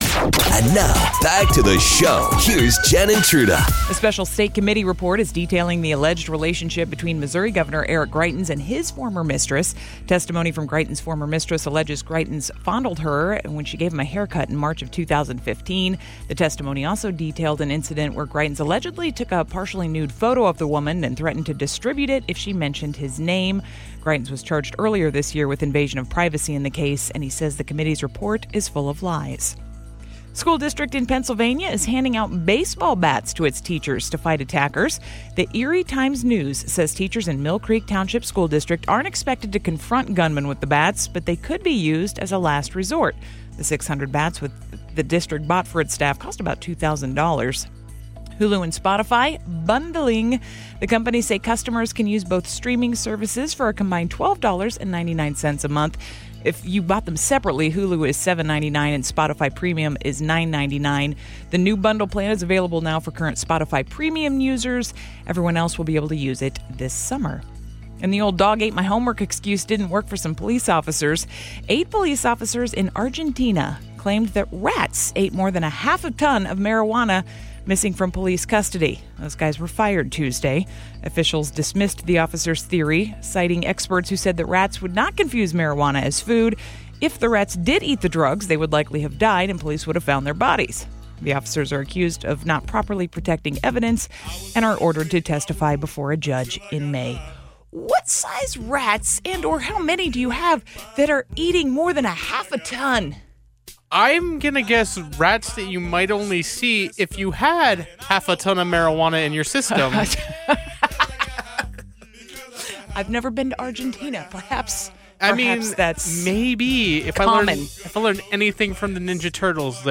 We hear some amazing stories from women who have had absolutely terrible first dates and even worse first date topics!